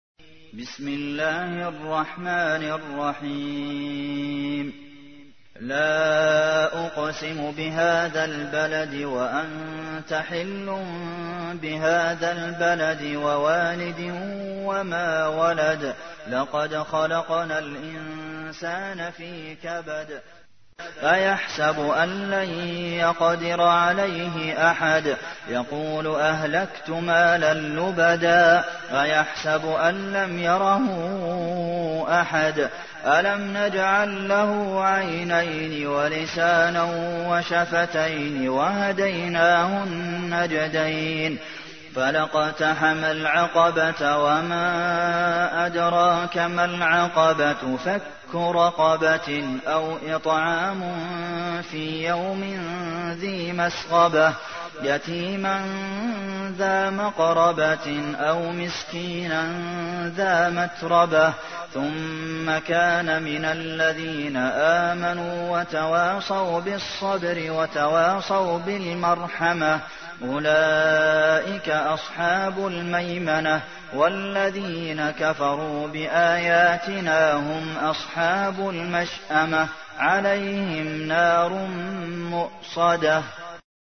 تحميل : 90. سورة البلد / القارئ عبد المحسن قاسم / القرآن الكريم / موقع يا حسين